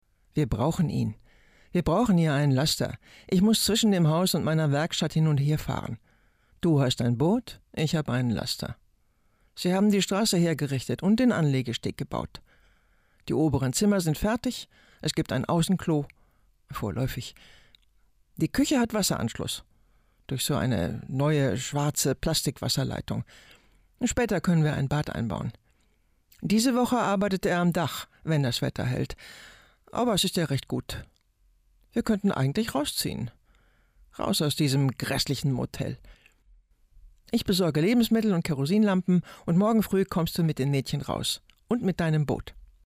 markant
Alt (50-80)
Audio Drama (Hörspiel), Audiobook (Hörbuch), Scene